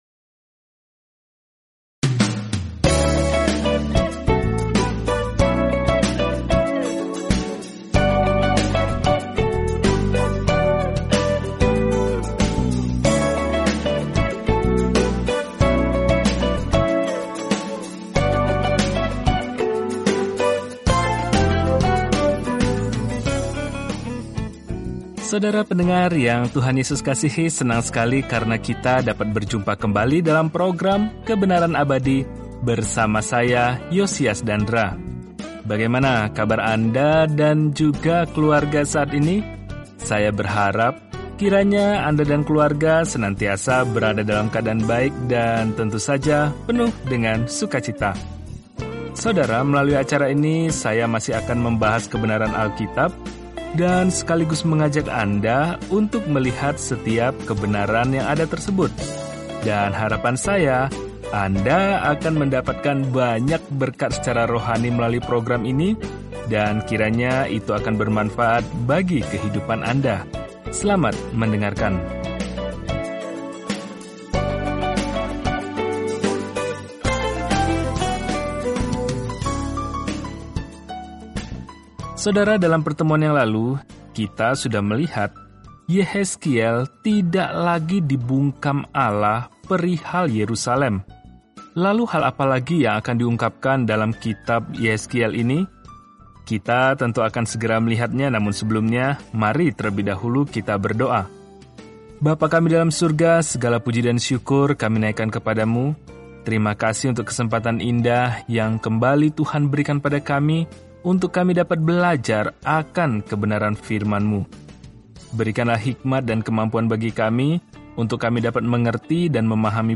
Firman Tuhan, Alkitab Yehezkiel 33:23-33 Yehezkiel 34:1-28 Hari 19 Mulai Rencana ini Hari 21 Tentang Rencana ini Orang-orang tidak mau mendengarkan peringatan Yehezkiel untuk kembali kepada Tuhan, jadi dia malah memerankan perumpamaan apokaliptik, dan itu menusuk hati orang-orang. Jelajahi Yehezkiel setiap hari sambil mendengarkan pelajaran audio dan membaca ayat-ayat tertentu dari firman Tuhan.